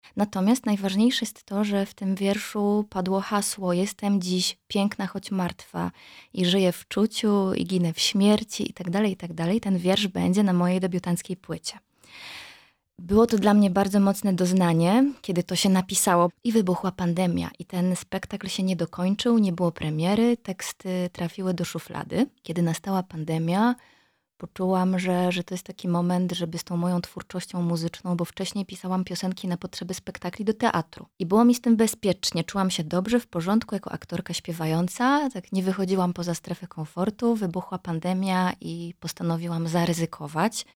W rozmowie w studiu Radio Rodzina aktorka opowiada o genezie projektu muzycznego, sile teatru oraz autorskiej metodzie pracy z ciałem i emocjami.